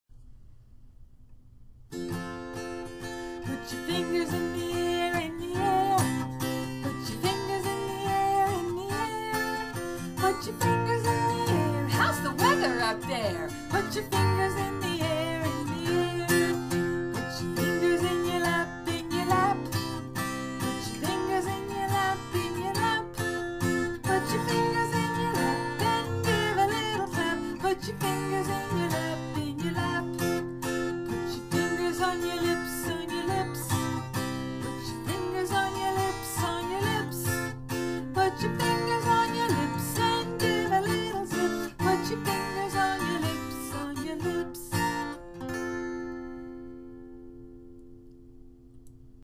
Tune: "If You're Happy and You Know it"